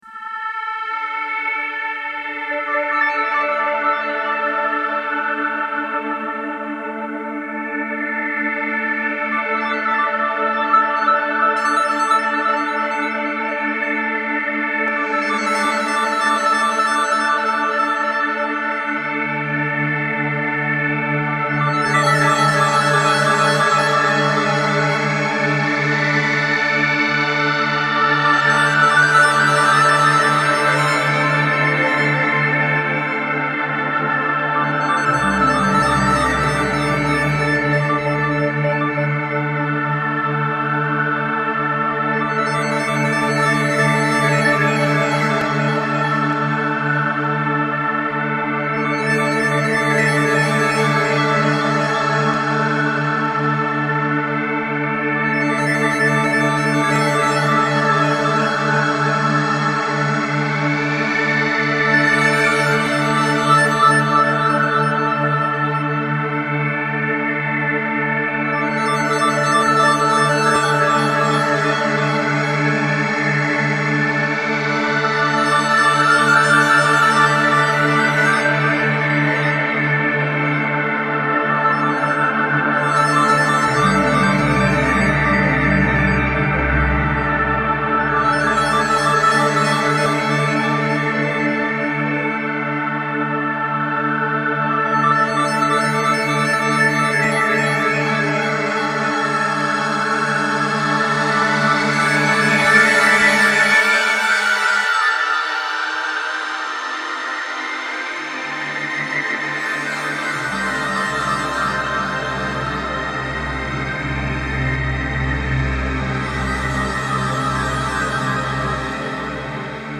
- Long Spheric Ambient Pads -
10 Golden Pad